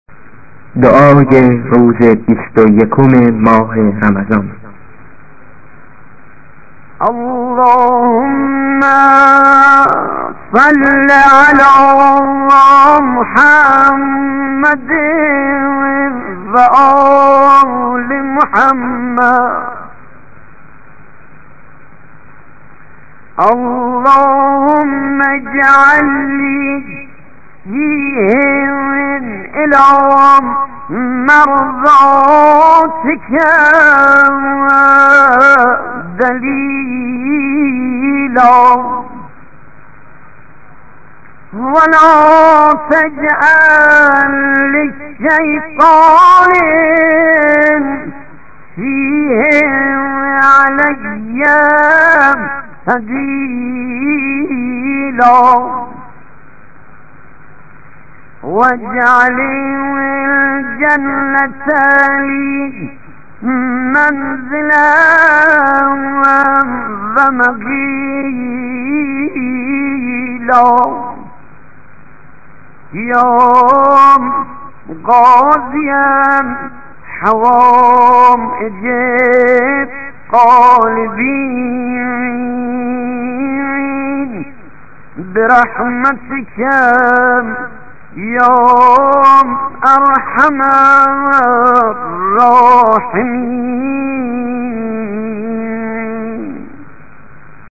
ادعية أيام شهر رمضان